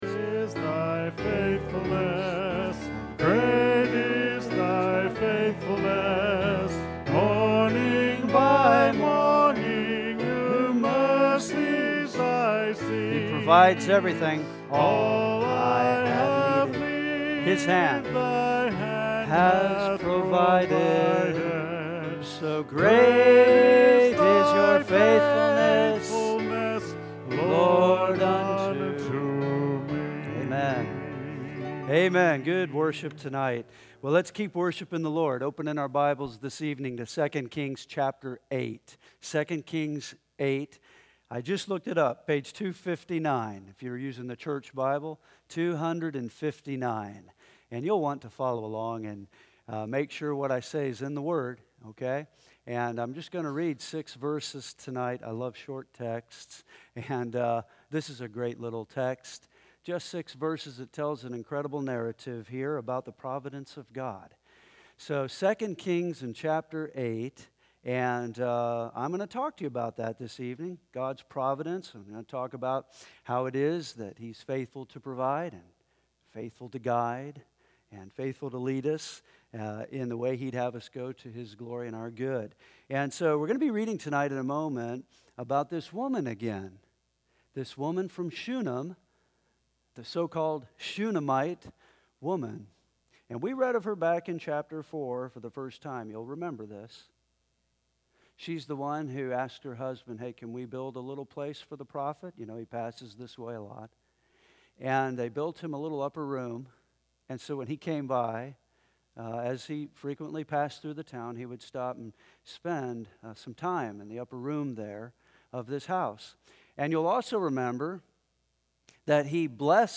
2016 The Good The Bad The Ugly 2 Kings This is an evening sermon with no manuscript attached.